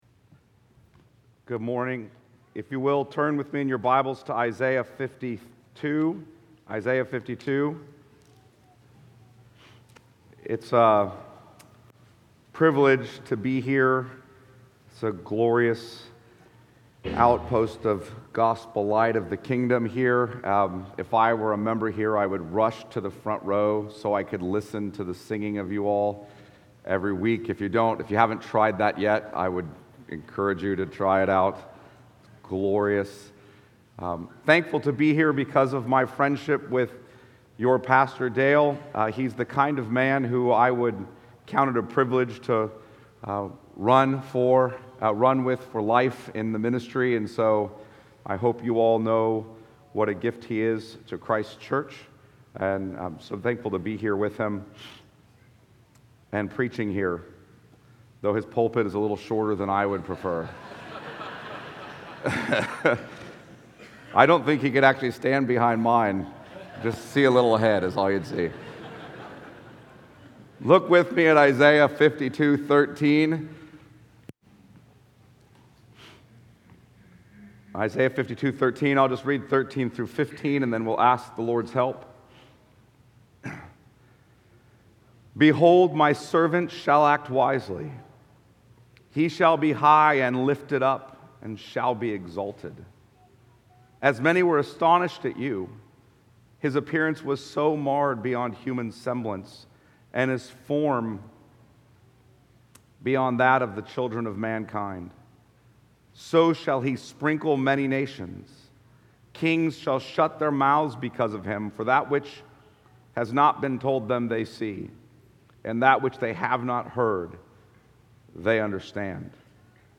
Harvest OPC Sermons Podcast - The Servant of the Nations | Free Listening on Podbean App